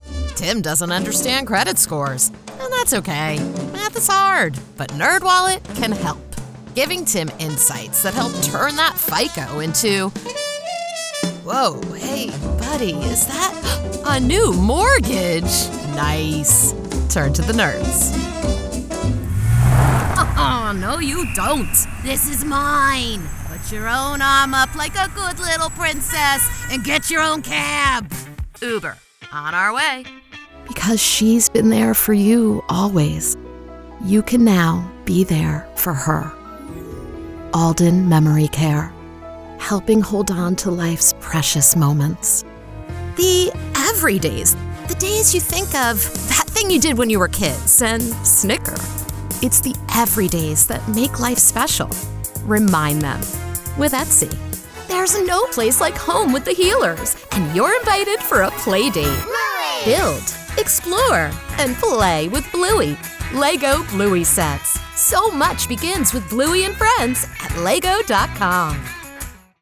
Never any Artificial Voices used, unlike other sites.
Foreign & British Female Voice Over Artists & Actors
Adult (30-50) | Yng Adult (18-29)